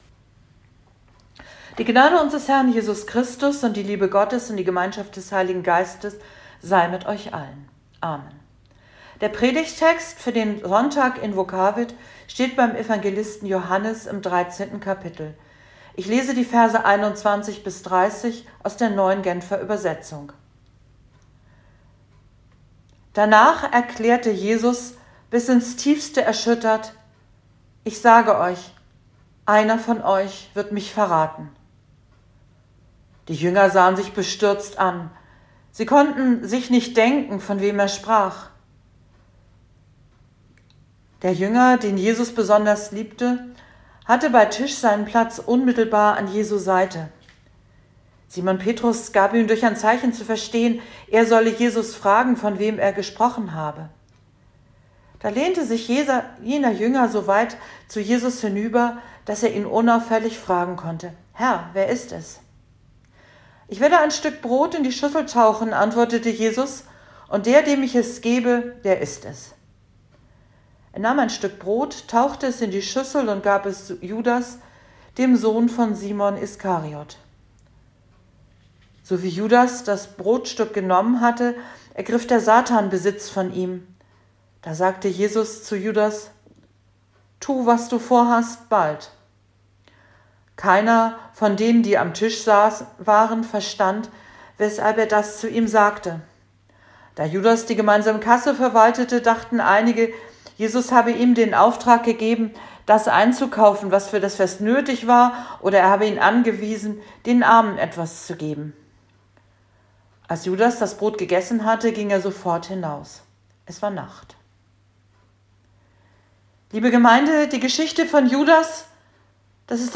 Predigt zum Sonntag Invokavit 2021